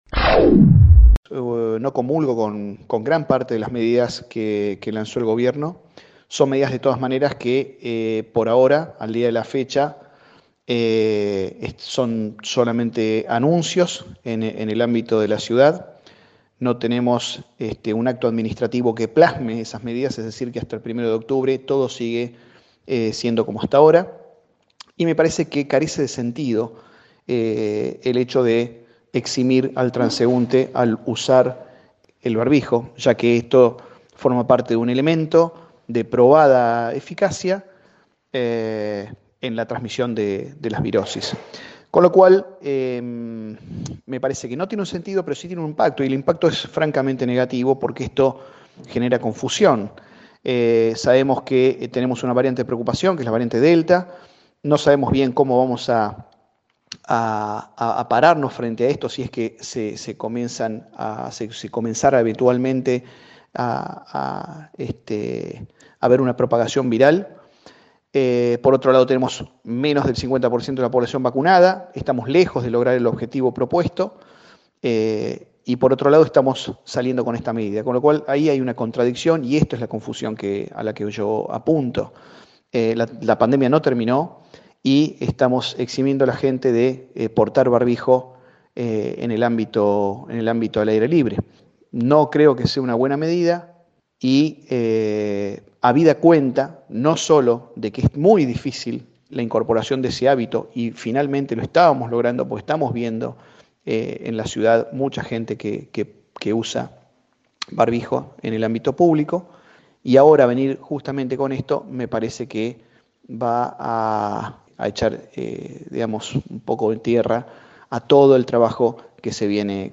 El director de salud, en diálogo con LT39, puntualizó: “hasta el primero de octubre todo sigue siendo como hasta ahora y me parece que carece de sentido el hecho de eximir al transeúnte al usar el barbijo”.